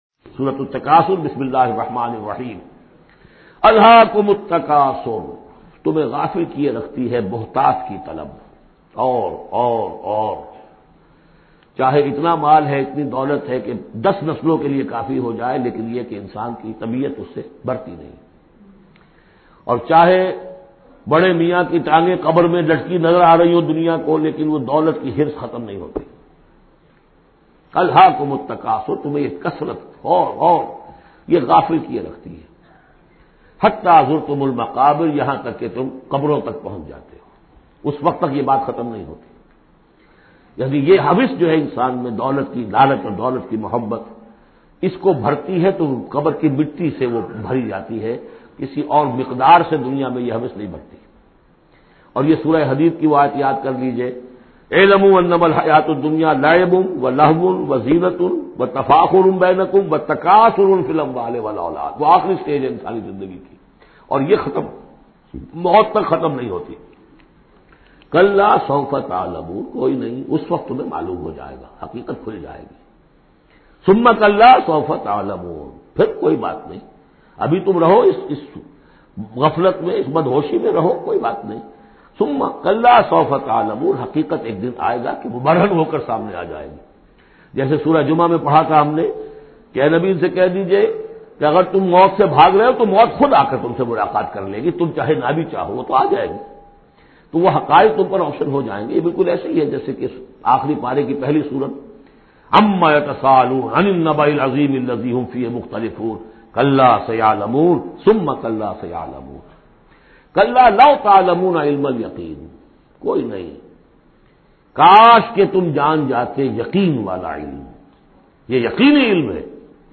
Surah Takasur Audio Tafseer by Dr Israr Ahmed
Surah Takasur, listen online mp3 audio tafseer in the voice of Dr Israr Ahmed.